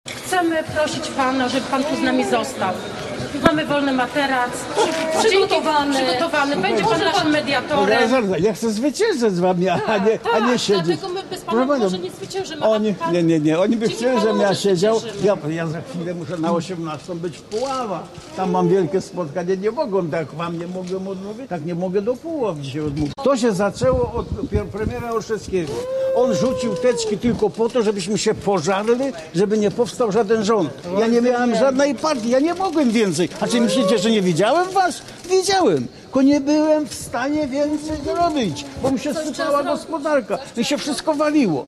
Rodzice i niepełnosprawni apelowali do byłego prezydenta, by z nimi został i był ich mediatorem: